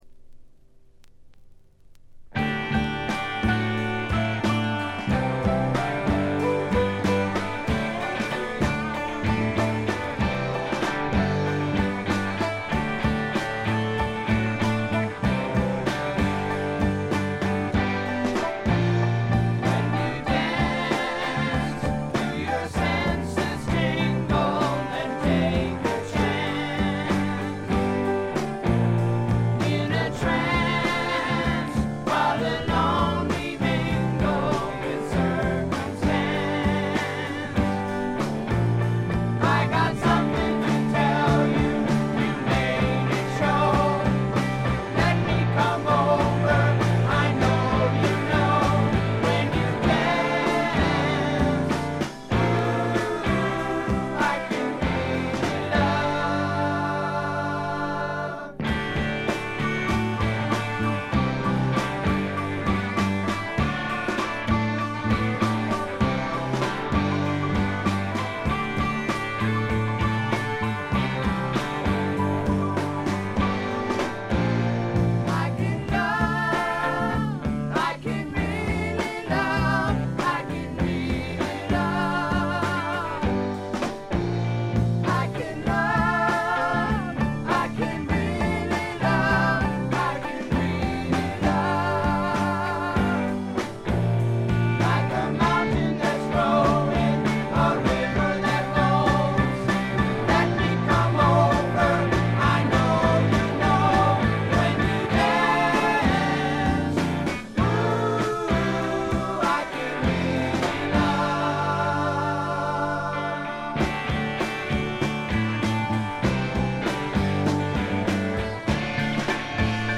試聴曲は現品からの取り込み音源です。
guitar, vocal